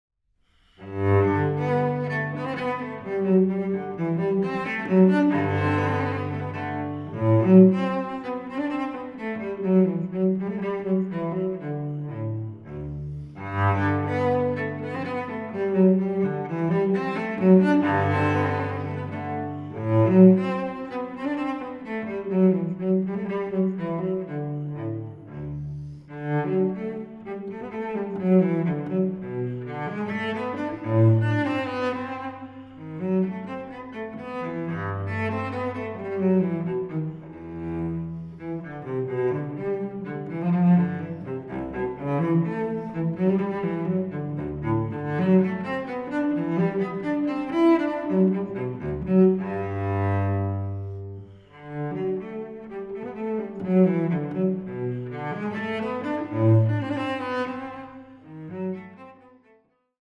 suites for solo cello
Cello